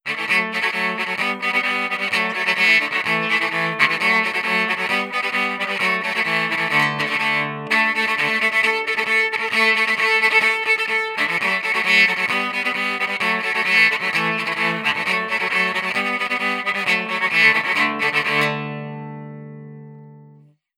• electric cello traditional DI sequence.wav
electric_cello_traditional_DI_sequence_JGI.wav